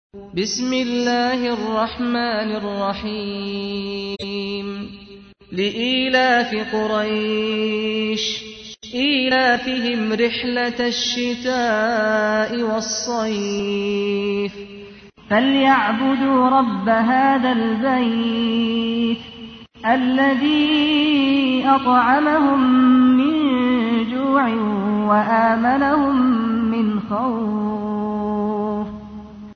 تحميل : 106. سورة قريش / القارئ سعد الغامدي / القرآن الكريم / موقع يا حسين